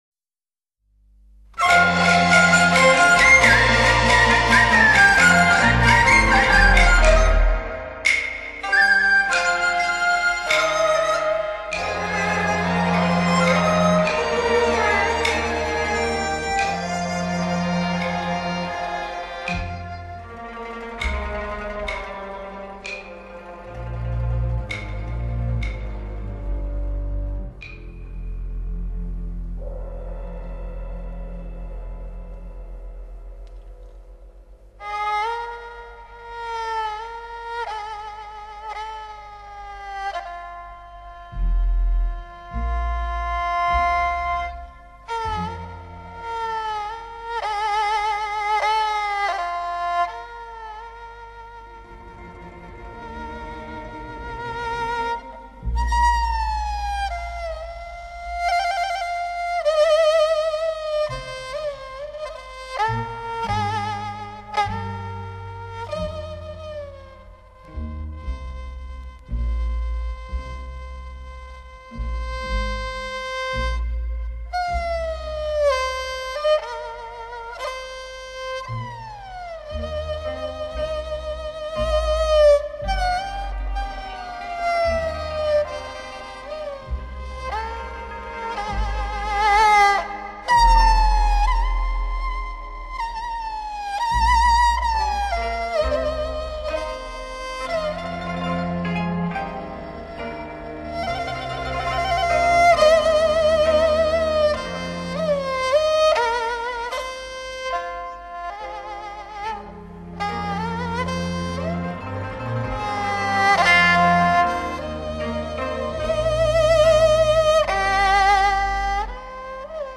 二泉映月 胡琴
板胡